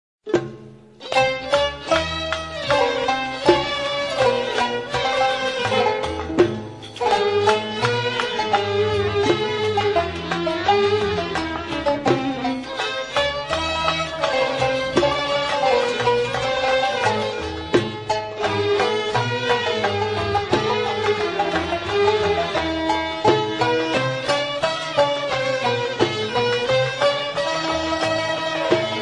Genres: Country (9)